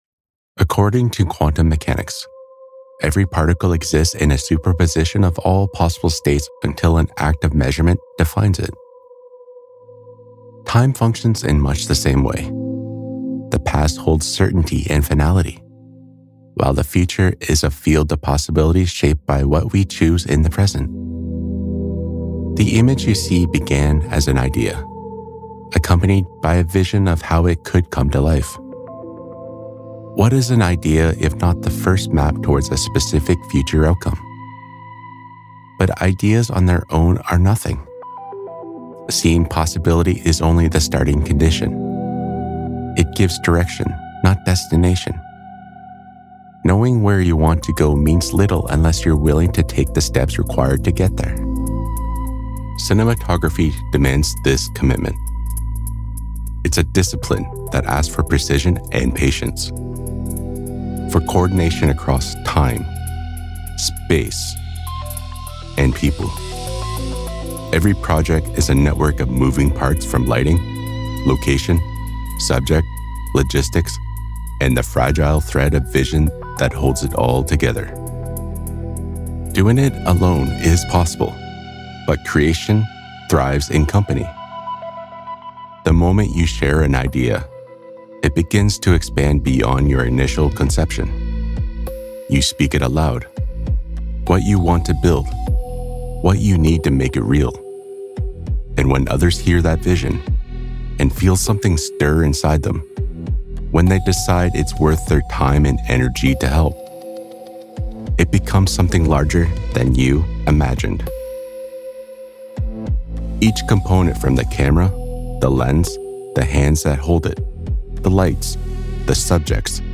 I have a neutral, southern British accent, but I can comfortably move between very colloquial (glottal stops etc) to received pronunciation, or "BBC English".
Self aware, dry comedy, Gritty, Smooth, deep, authoritative, classy, announcer, elegant, refined, powerful, military, humorous, dry, funny, sarcastic, witty, somber, poetic, storyteller, friendly, informative, ...